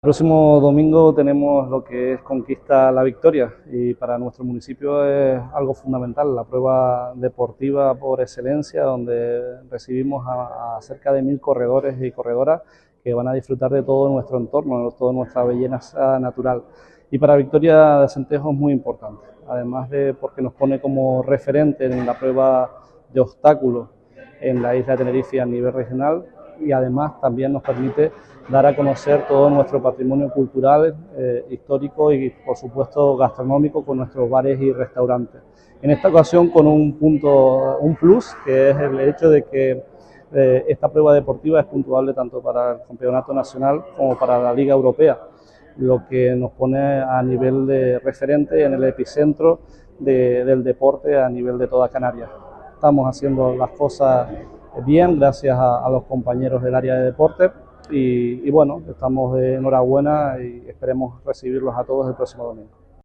Cuña promocional